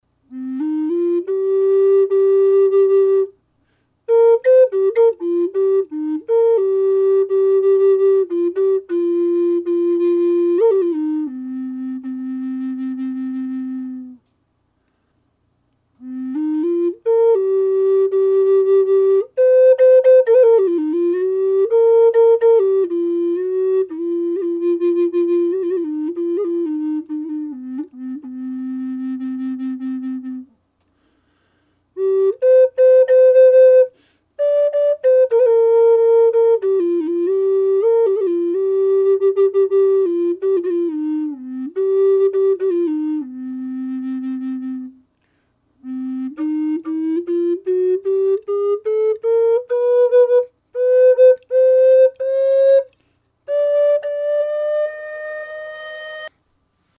Sound sample of  this Flute short melody + 16 notes scale  without reverb/raw unprocessed